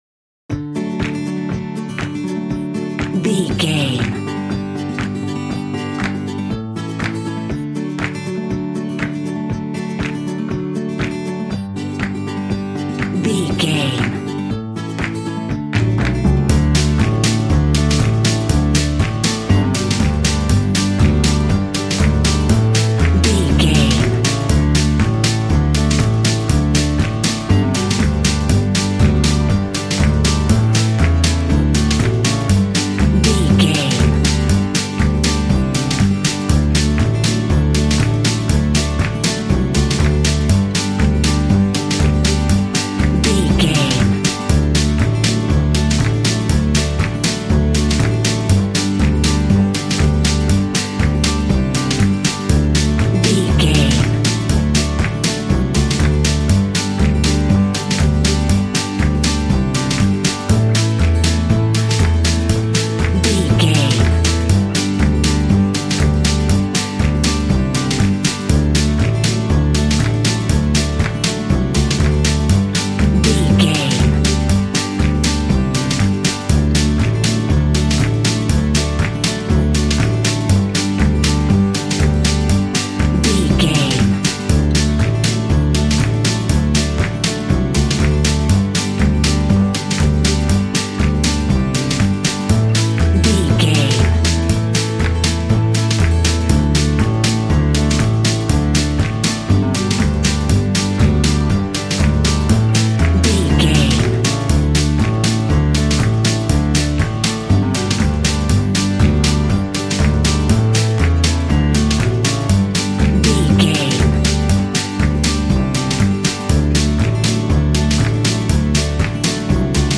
Happy and cute country music from the farmyard.
Ionian/Major
D
Fast
bouncy
double bass
drums
acoustic guitar